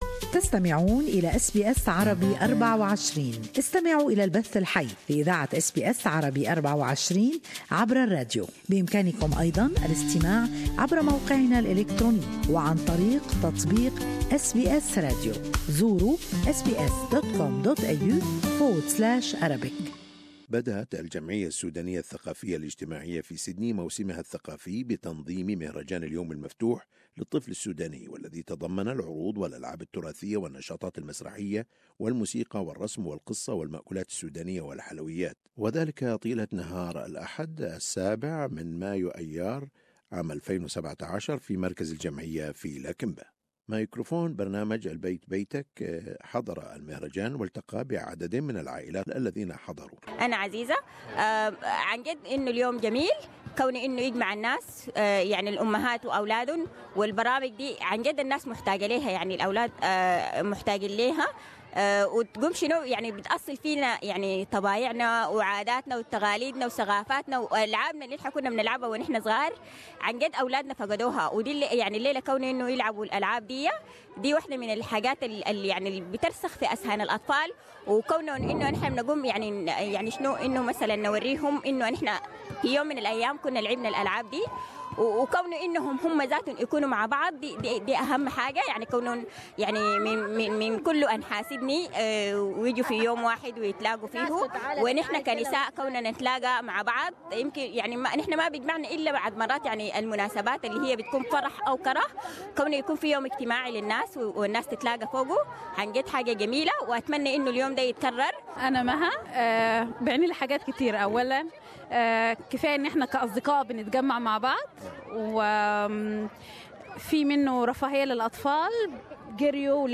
اقيم المهرجان طيلة نهار الأحد الماضي 11- مايو / ايار .في مركز الجمعية في لاكمبا